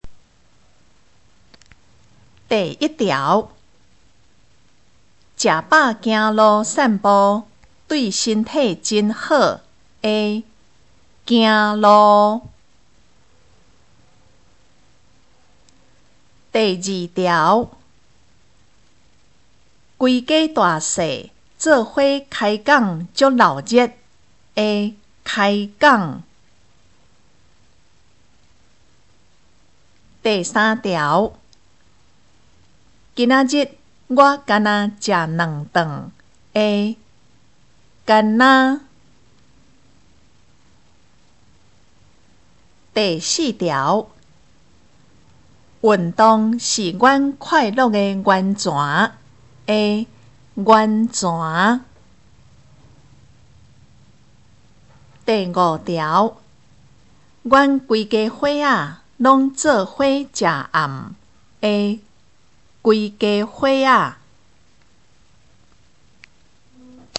【國中閩南語2】每課評量(3)聽力測驗mp3